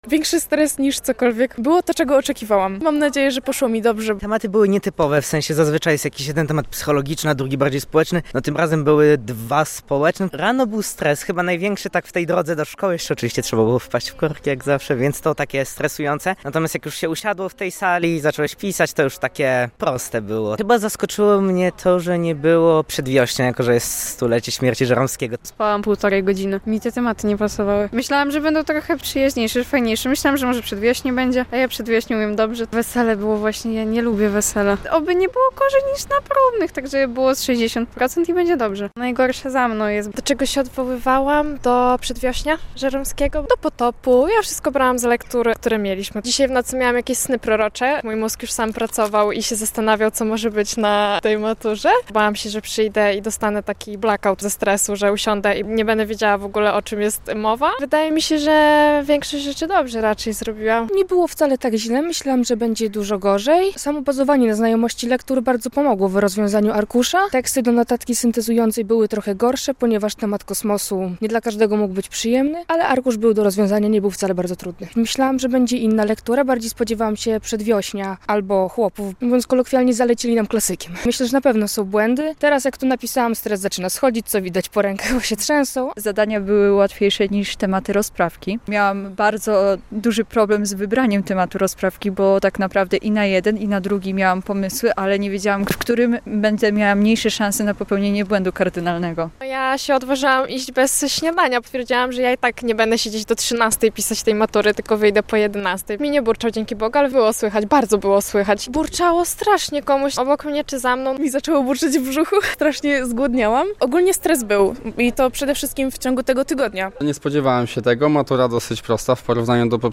Pierwsze opinie maturzystów - relacja